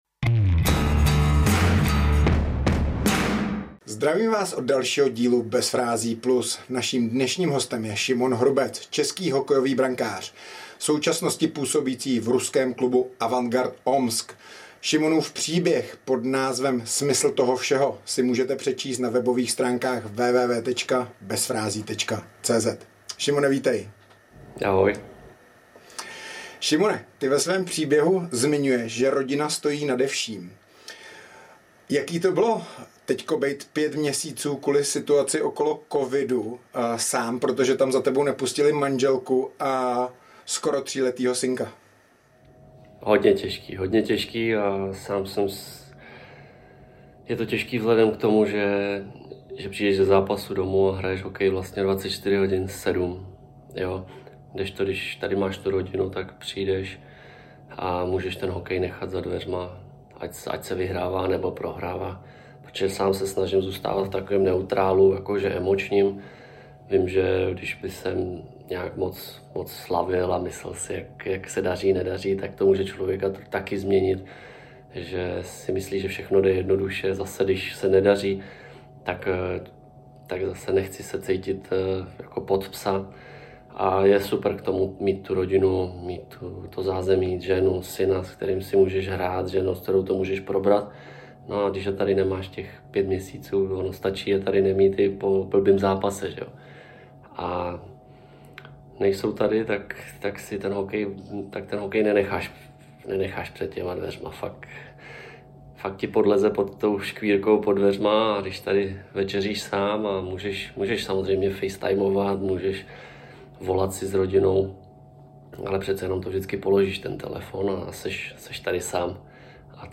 Hostem nového dílu Bez frází+ je brankář Avangardu Omsk a české reprezentace Šimon Hrubec. A mluvil v něm otevřeně nejen o charitativním projektu, díky kterému našel smysl toho všeho.